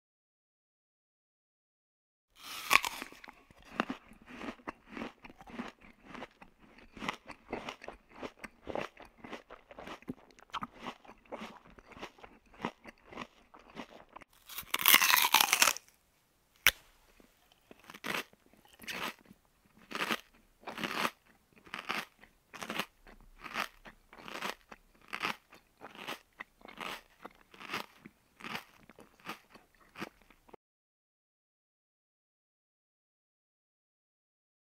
دانلود آهنگ غذا خوردن 2 از افکت صوتی انسان و موجودات زنده
دانلود صدای غذا خوردن 2 از ساعد نیوز با لینک مستقیم و کیفیت بالا
جلوه های صوتی